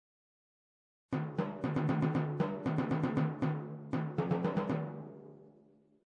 timpani.mp3